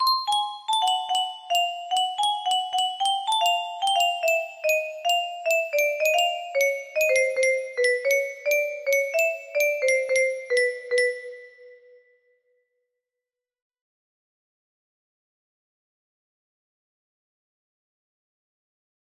melody_09 music box melody